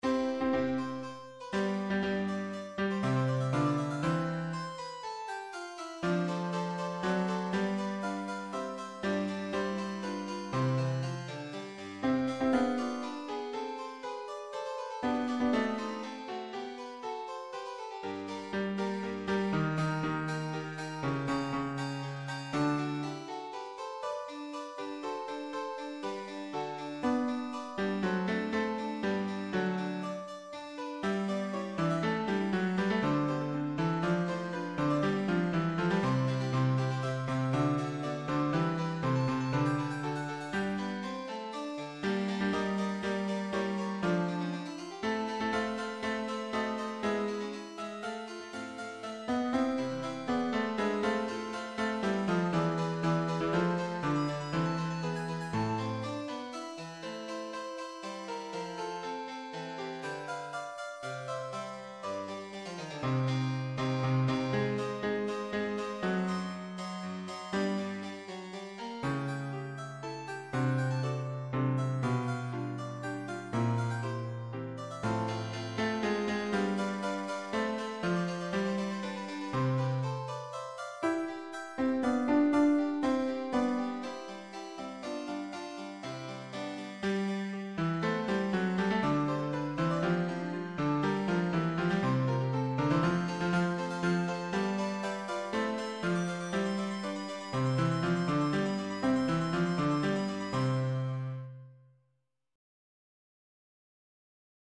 Sans paroles
Voix + chœur en sourdine